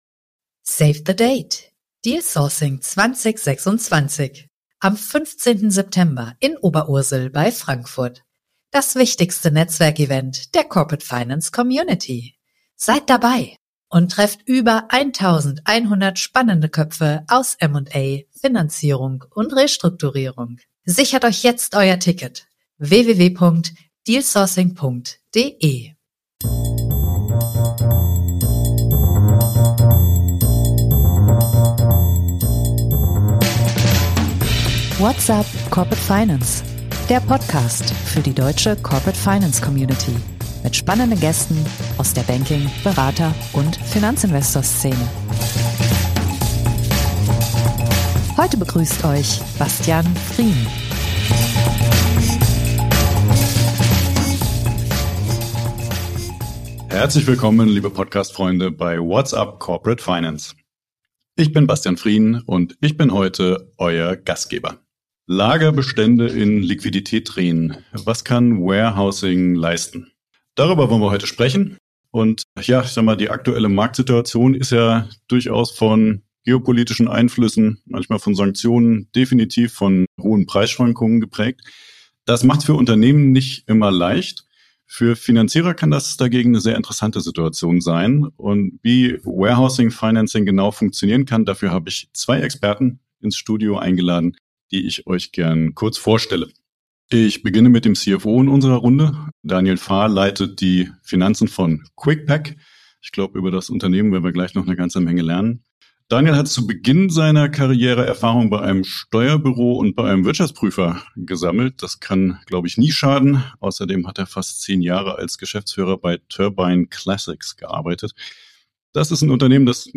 Doch die kostet Geld – ist Financial Warehousing dafür das geeignete Finanzierungsinstrument? Darüber haben wir mit einem CFO und einem Finanzierer in dieser Episode gesprochen.